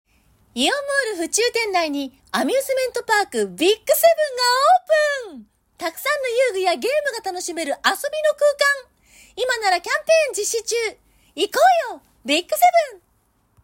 ナレーション音源サンプル　🔽
【元気系ナレーション】